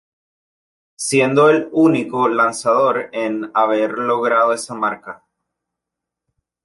lo‧gra‧do
/loˈɡɾado/